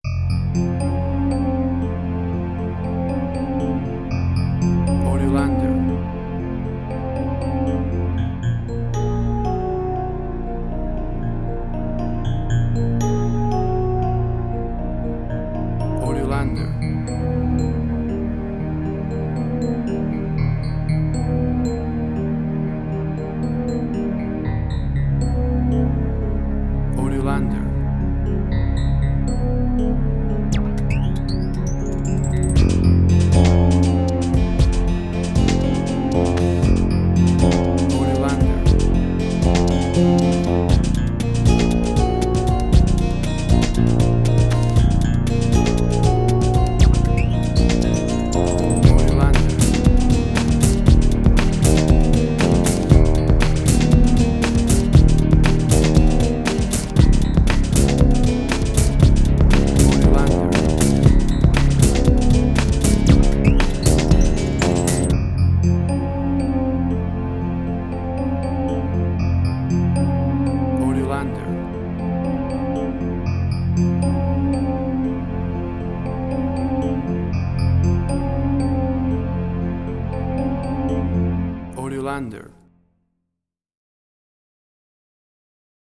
Ambient Strange&Weird
Tempo (BPM): 118